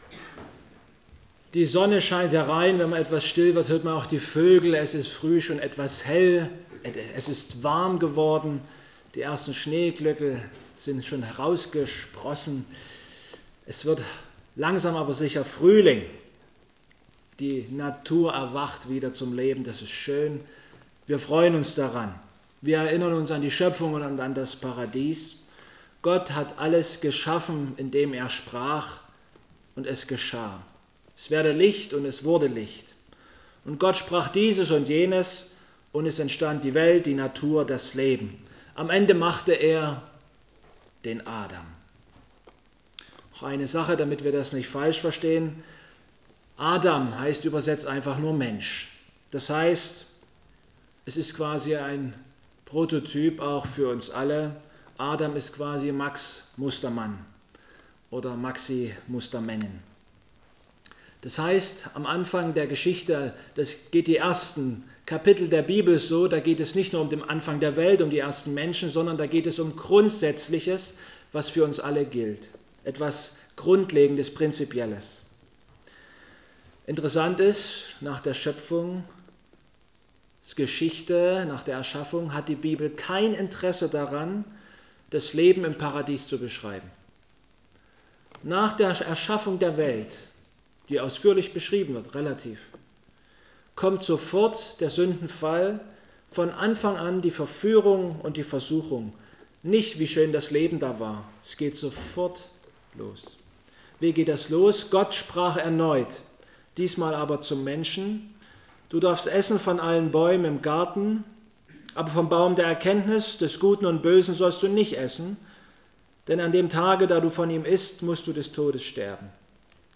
Mose 3 Gottesdienstart: Abendmahlsgottesdienst Vergangene Woche war Fasching.